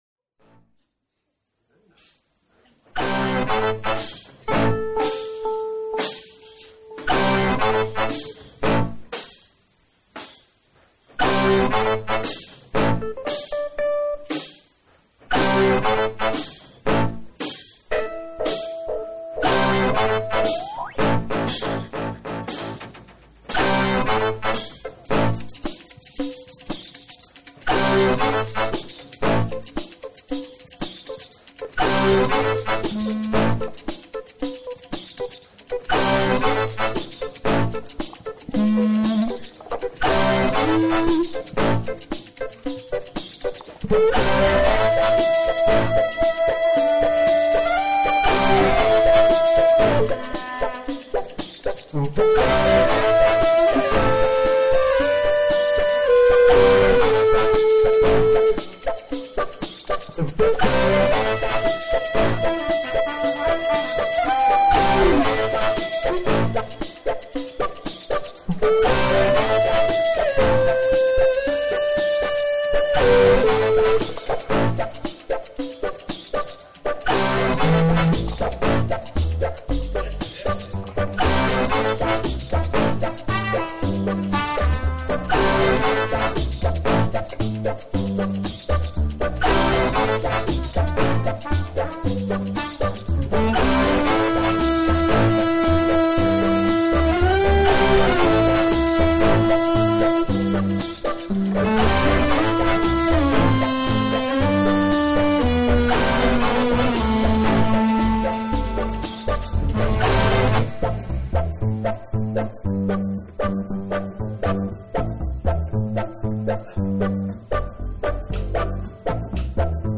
calypso, blues, loop, trip
zwischen geloopter Improvisation und akustischem Calypso.
Trumpet/Vocals
Saxophone/Clarinet
Kontrabass